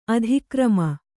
♪ adhikrama